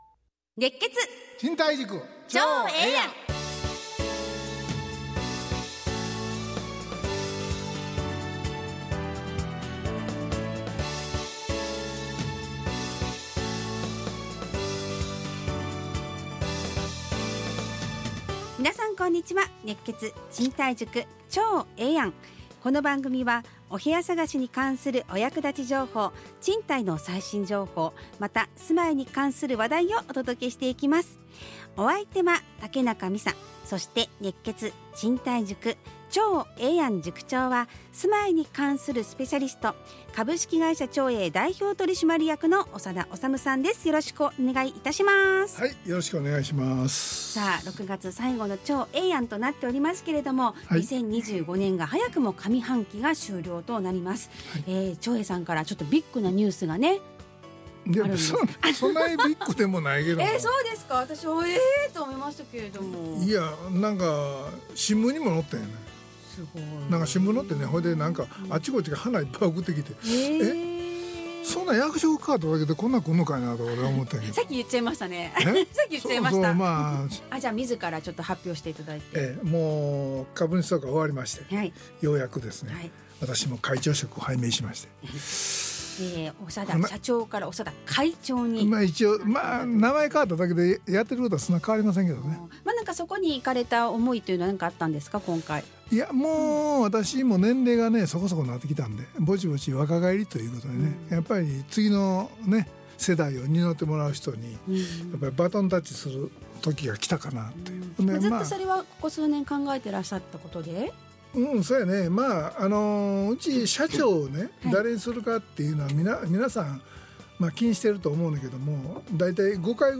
ラジオ放送 2025-06-27 熱血！